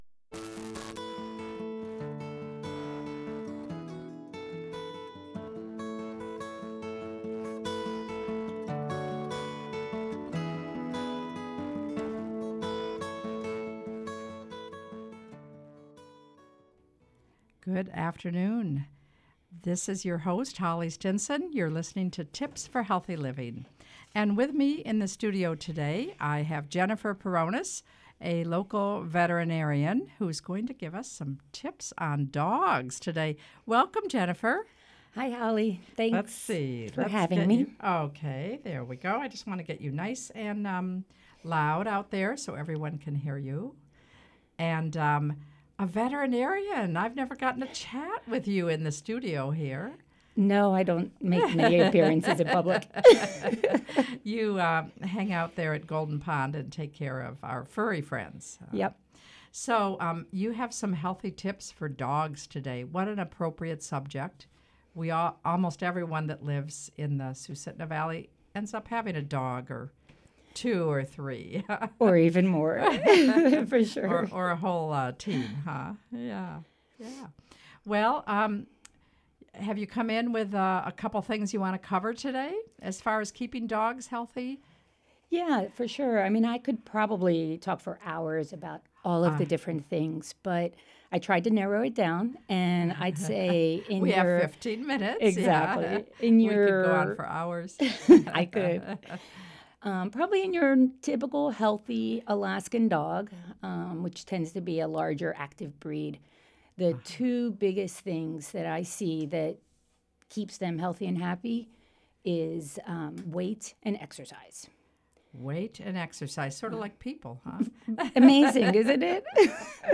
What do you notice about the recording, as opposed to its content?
A live 15-minute conversation about health and wellness from health care providers in our communities. Today’s tips for healthy living are for dogs!